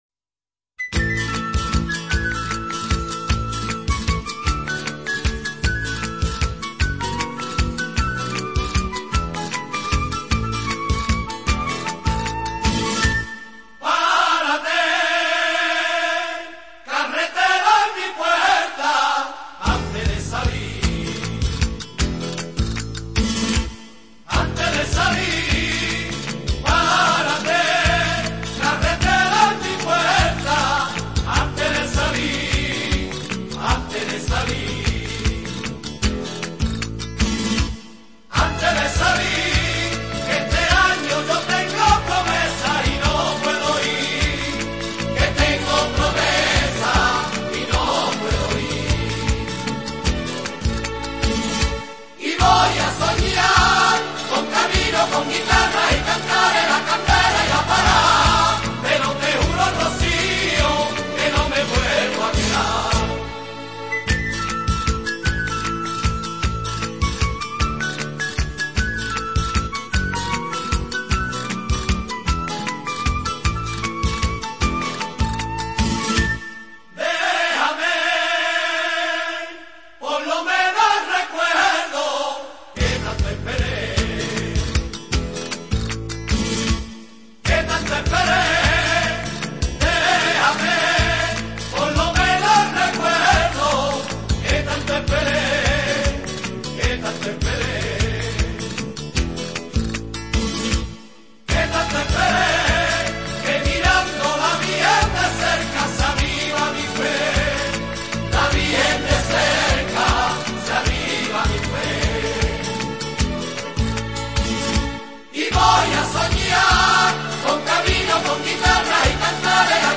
Guitarra
Temática: Rociera